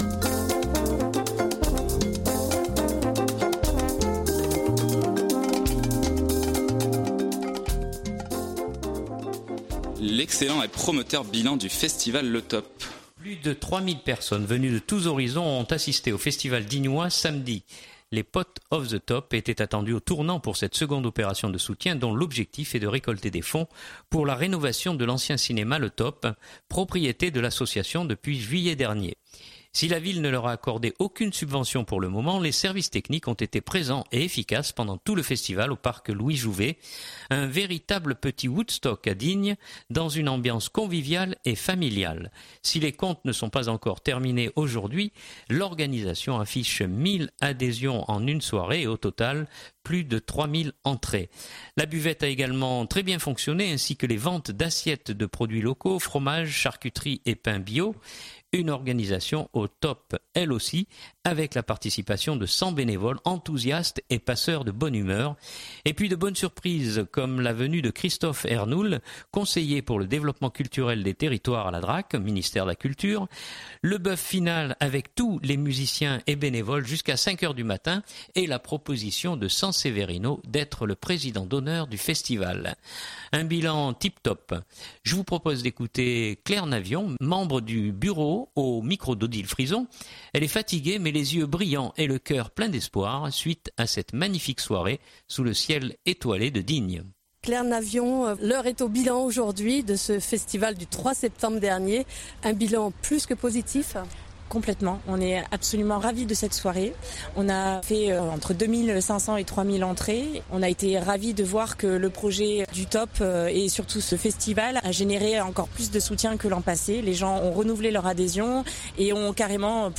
Elle est fatiguée mais les yeux brillants, et le cœur plein d’espoir suite à cette magnifique soirée sous le ciel étoilé de Digne.